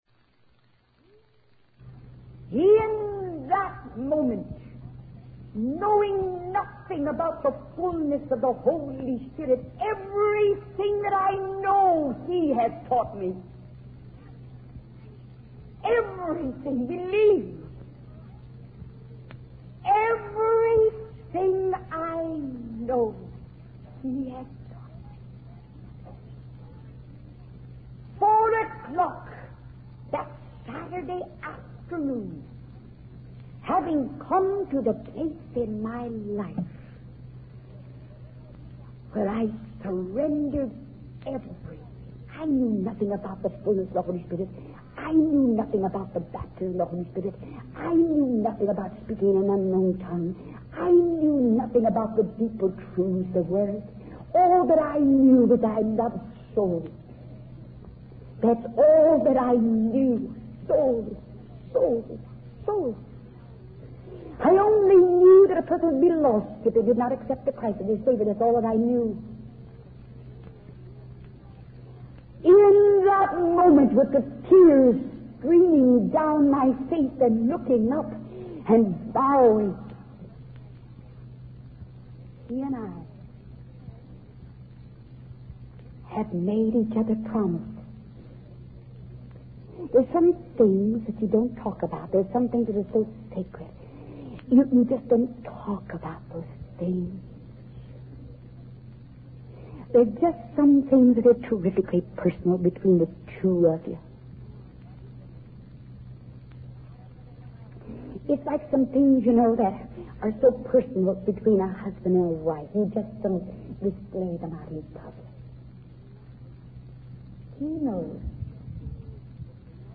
In this sermon, the speaker shares a personal experience of surrendering everything to God and receiving the fullness of the Holy Spirit.